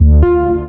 Synths
ED Synths 06.wav